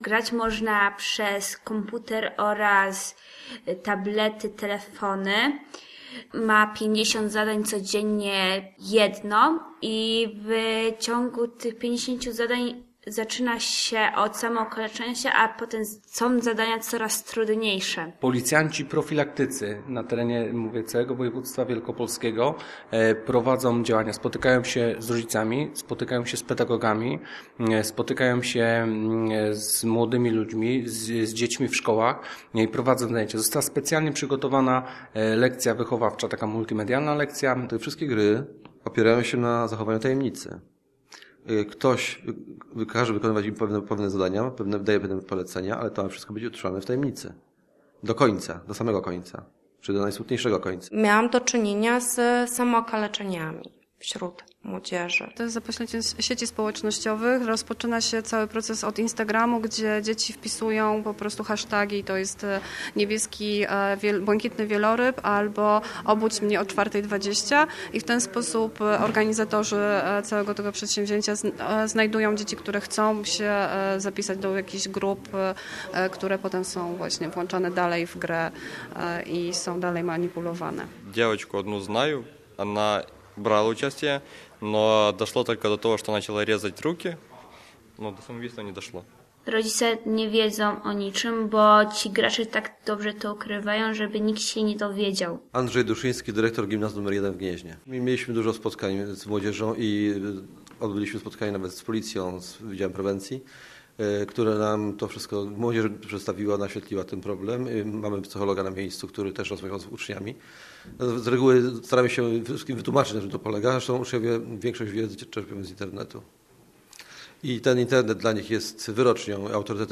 Hasztag: gry dziecięce - reportaż